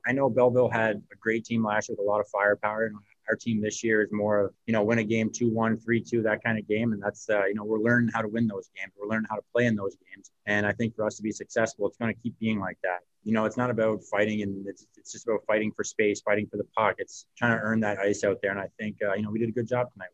Speaking to media post game